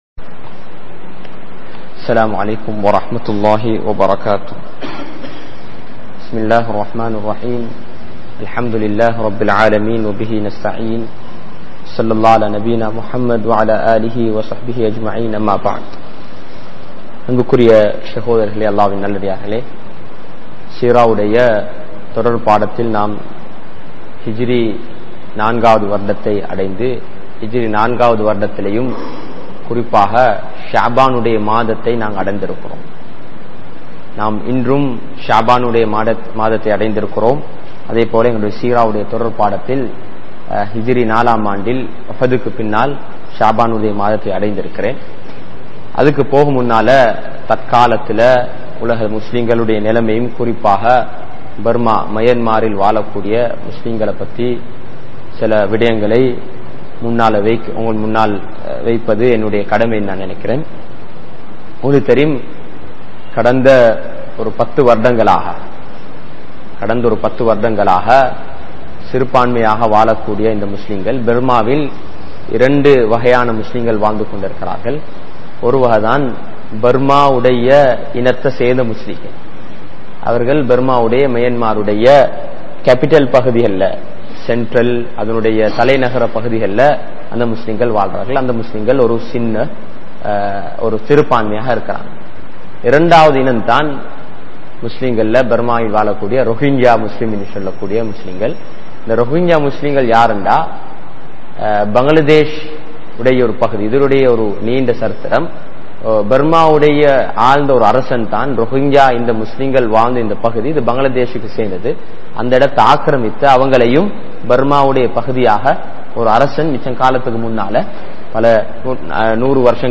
Seerah Of Prophet Muhammed(SAW) | Audio Bayans | All Ceylon Muslim Youth Community | Addalaichenai
Muhiyadeen Jumua Masjith